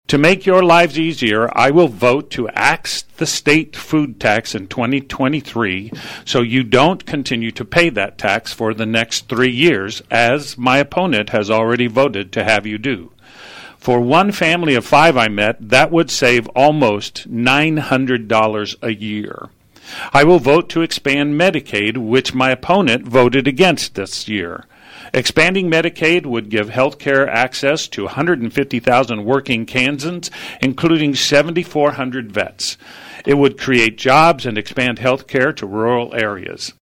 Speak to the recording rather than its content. The candidates for the Kansas House 60th District position clashed on several topics during KVOE’s final Candidate Forum of this general election cycle.